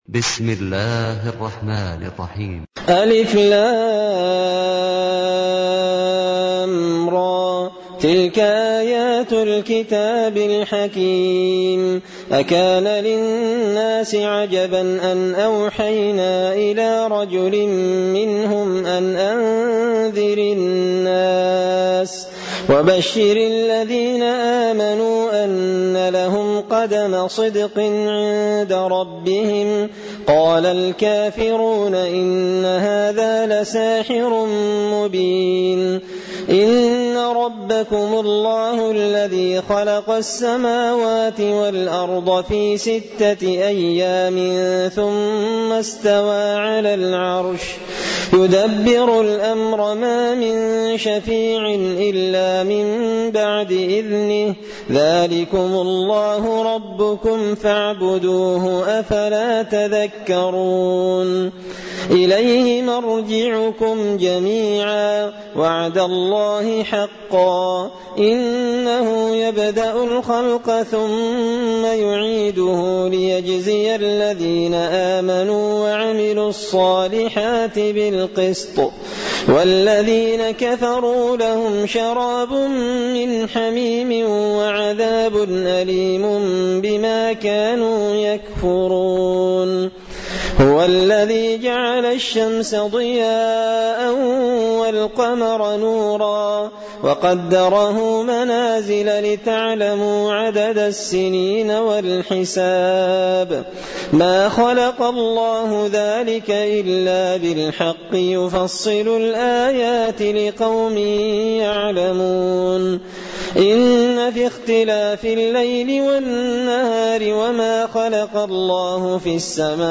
تلاوة سورة يونس
دار الحديث بمسجد الفرقان ـ قشن ـ المهرة ـ اليمن